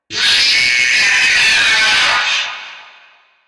Lightning Sonic Jumpscares Sound 2 Téléchargement d'Effet Sonore